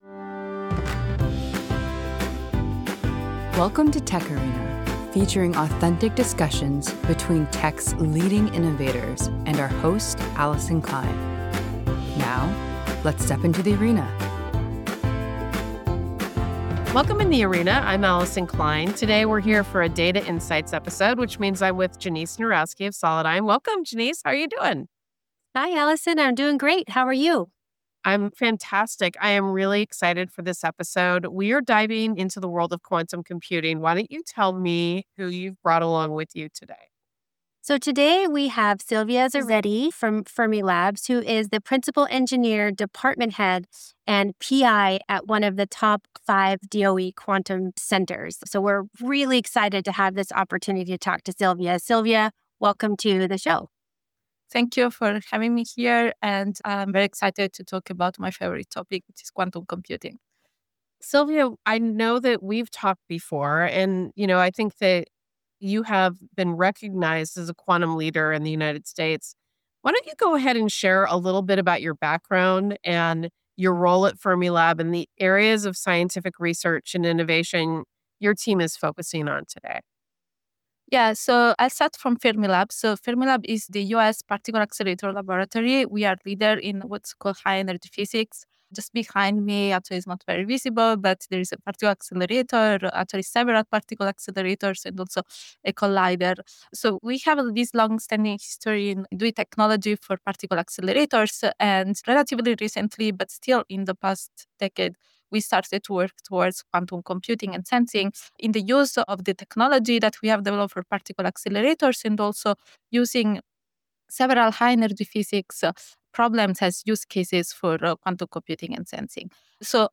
In The Arena by TechArena is your source for authentic conversations with the leading innovators in technology.